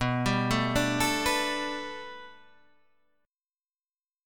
Bm7#5 Chord